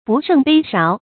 不胜杯杓 bù shèng bēi sháo 成语解释 不胜：经不起；杓：舀东西的器具；杯杓：泛指酒器。比喻喝酒太多，醉了 成语出处 西汉 司马迁《史记 项羽本纪》：“张良入谢曰：‘沛公 不胜杯杓 ，不能辞。’”